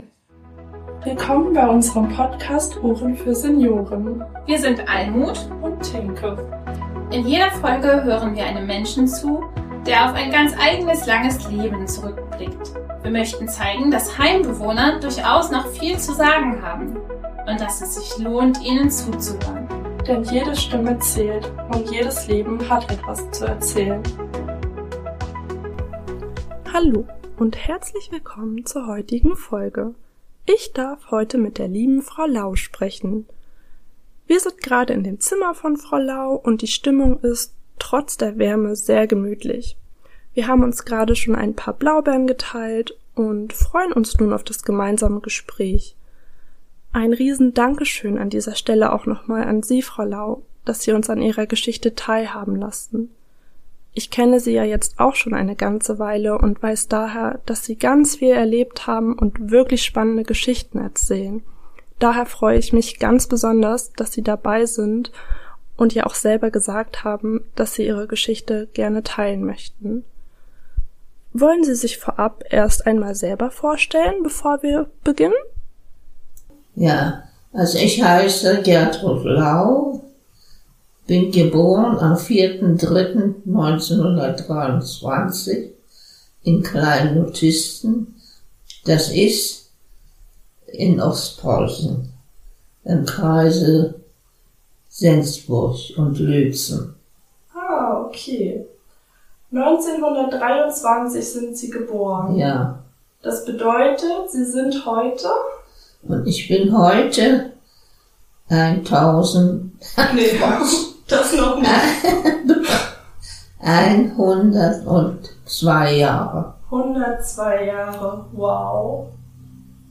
In dieser Lebensgeschichte werden wir von einer 102-jährigen Heimbewohnerin mit in die dunklen Tiefen ihrer Vergangenheit genommen. Sie erzählt von ihrer Kriegsgefangenschaft in Sibirien und wie es trotz aller Härte immer weitergehen musste.